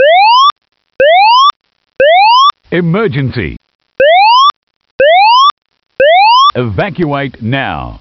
Announcement Tones
“Verbal tones” are the new AS1670.4 ISO T3 tones with standard voice messages